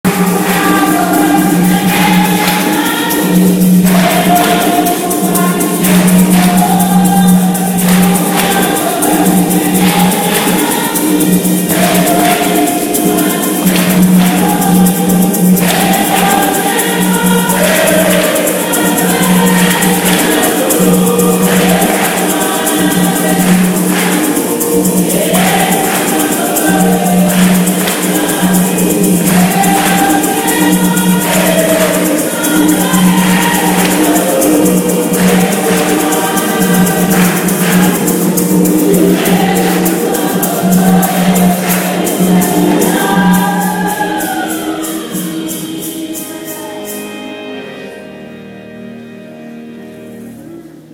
Après une messe aux résonnances africaines en la superbe église de Marolles, nous avons partagé un repas d'une tès forte convivialité.